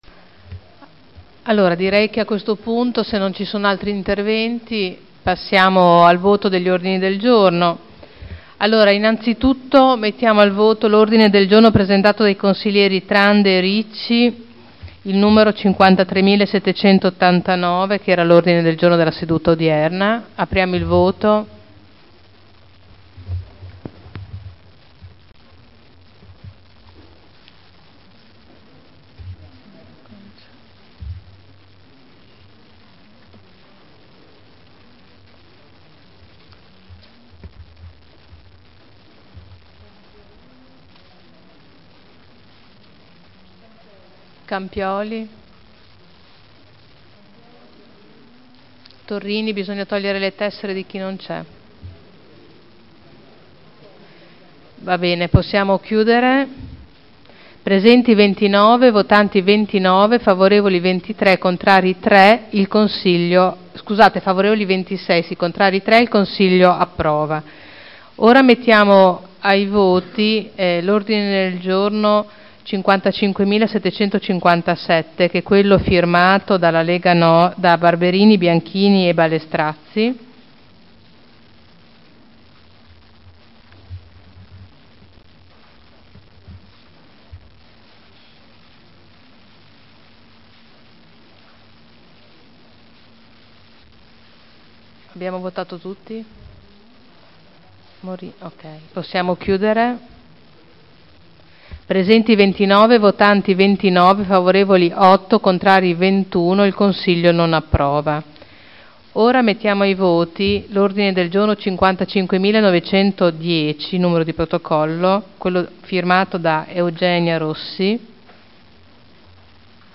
Il Presidente Caterina Liotti mette ai voti gli Ordini del Giorno n.53789 approvato, n.55757 respinto, n.55910 approvato.